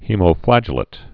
(hēmō-flăjə-lāt, -lĭt, -flə-jĕlĭt)